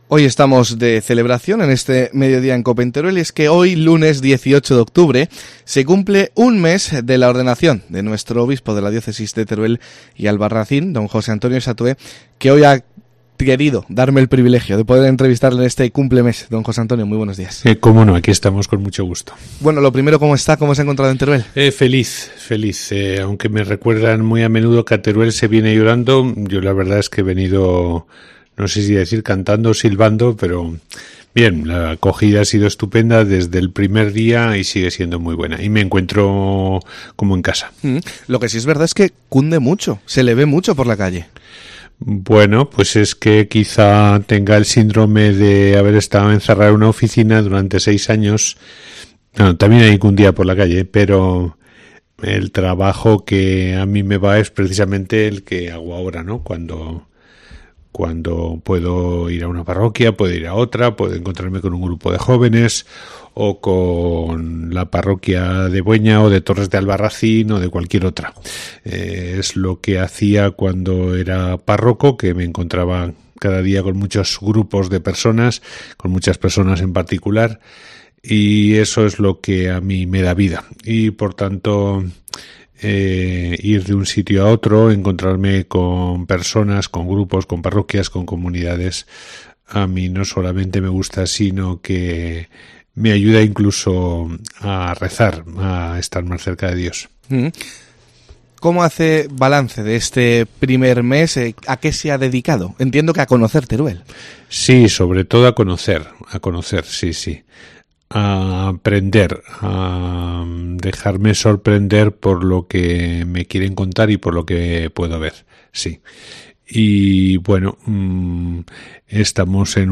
Así lo manifestaba Don José Antonio en los micrófonos de Mediodía COPE en Teruel.